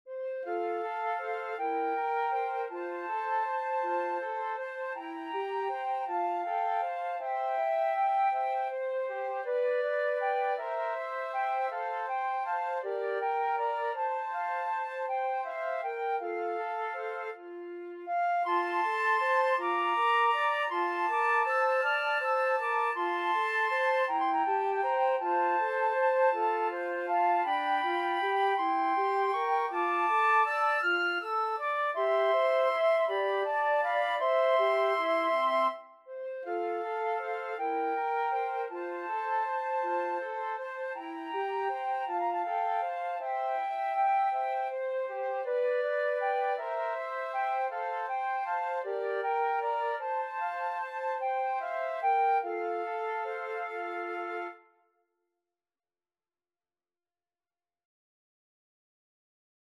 Andante
6/8 (View more 6/8 Music)
Flute Trio  (View more Intermediate Flute Trio Music)
Pop (View more Pop Flute Trio Music)